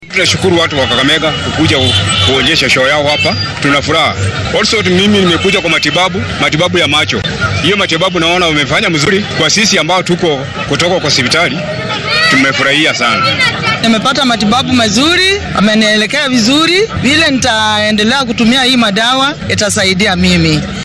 Dadka ayaa laga baarayay xanuunnada kansarka , macaanka ,aragga , baahiyaha gaarka ah iyo baaritaan guud oo la marinayay. Labo ka mid ah shakhsiyeedkii ka faa’iiday daryeelka caafimaad ee bilaashka ah ayaa dareenkooda la wadaagay warbaahinta.